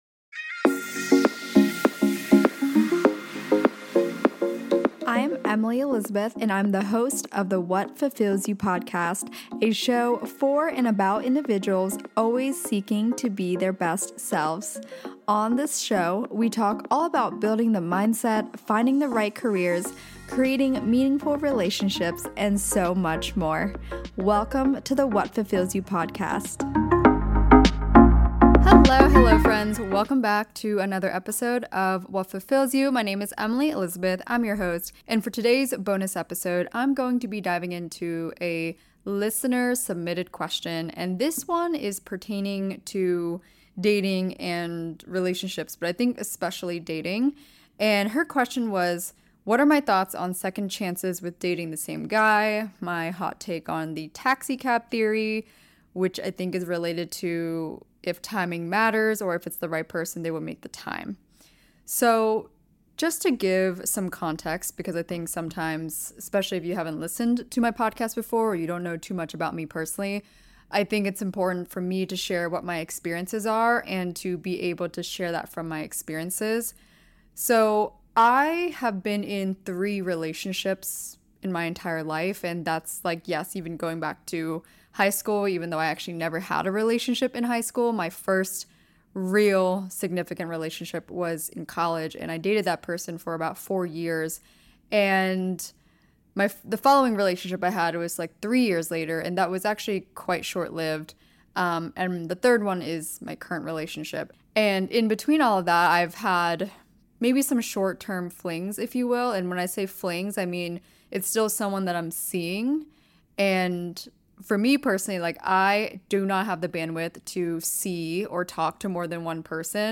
On this solo episode